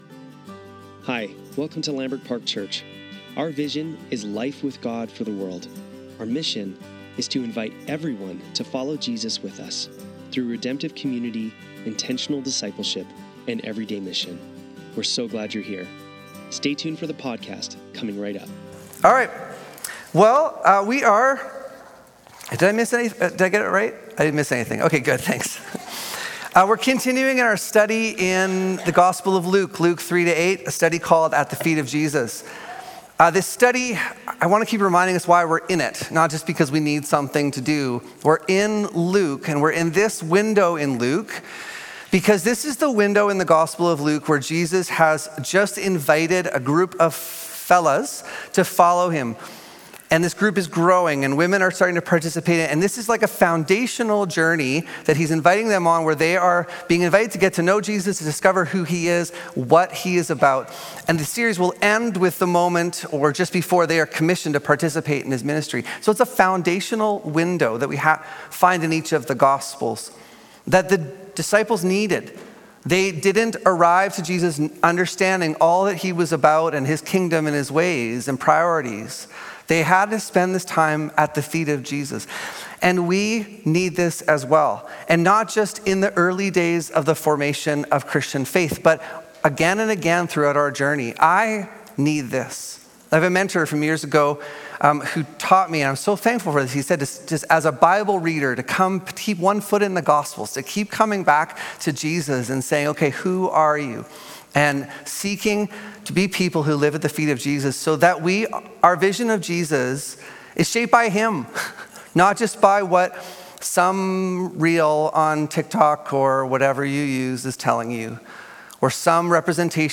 Sunday Service - June 1, 2025